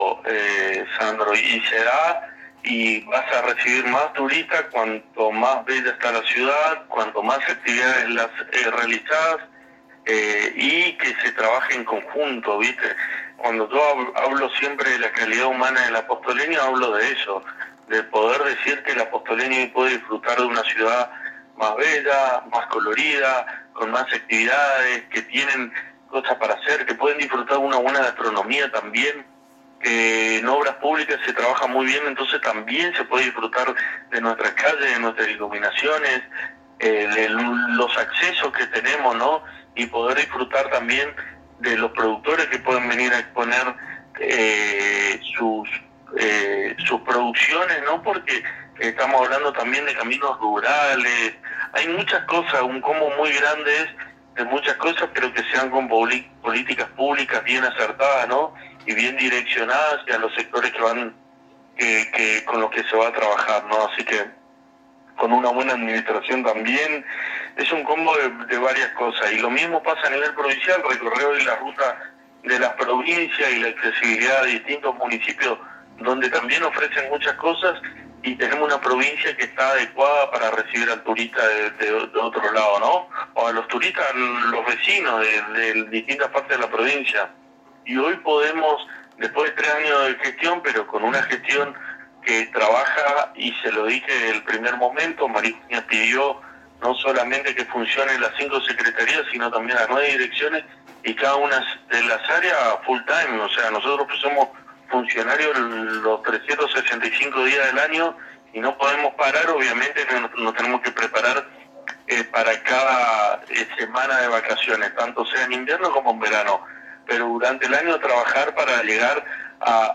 El Secretario de Gobierno de la Municipalidad de Apóstoles, Gastón Casares, en dialogo exclusivo con Éxito FM y la A.N.G. comentó sobre el arduo trabajo que viene realizando el Municipio encabezado por la Intendente María Eugenia Safrán en favor de todos los vecinos y en todas las áreas de Gobierno ya que se hace un trabajo integral.